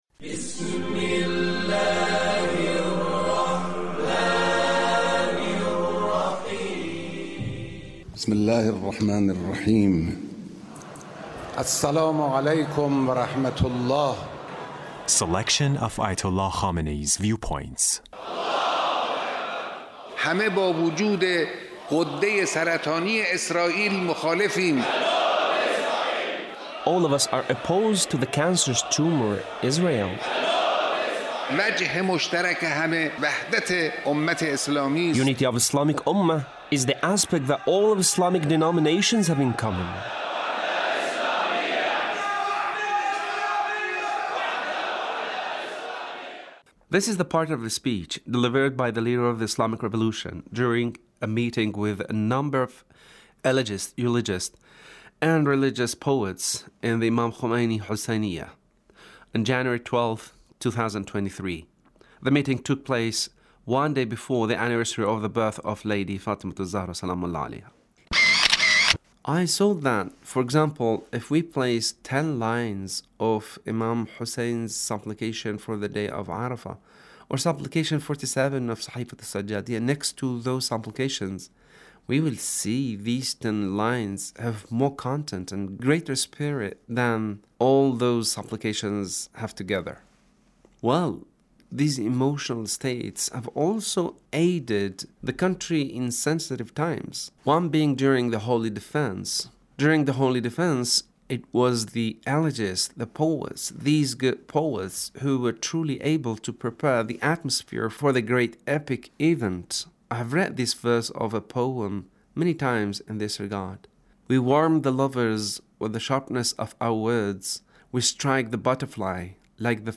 Leader's Speech meeting with Eulogists